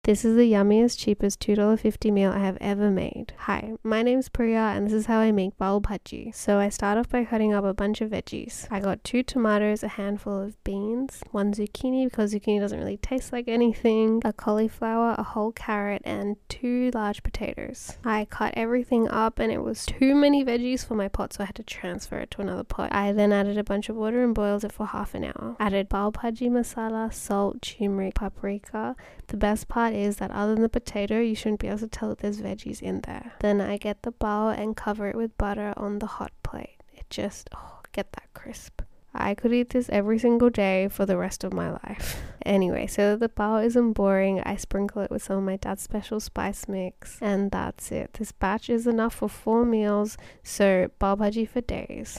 Your voice is so soothing 🥺🥺
Your voice is very satisfying